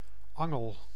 Ääntäminen
Synonyymit pen vishaak prikkel stekel Ääntäminen Tuntematon aksentti: IPA: /ˈɑŋəl/ Haettu sana löytyi näillä lähdekielillä: hollanti Käännöksiä ei löytynyt valitulle kohdekielelle.